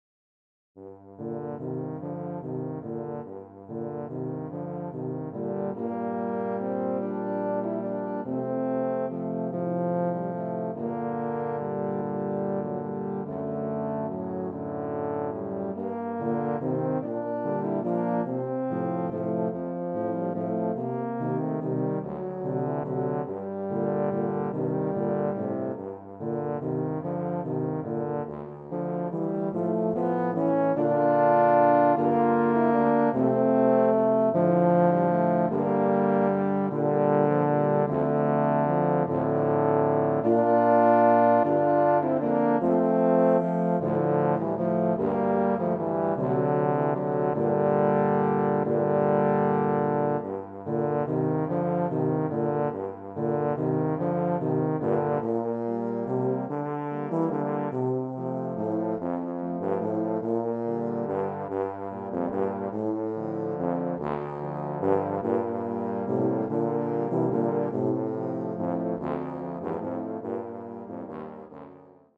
Voicing: Tuba / Euphonium Quartet